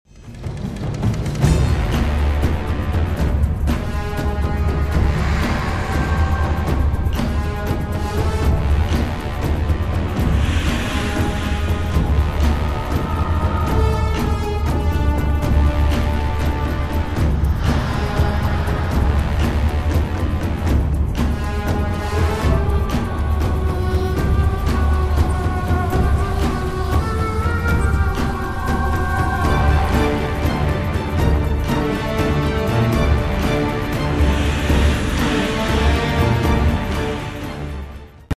... und schön düster und unheilvoll ...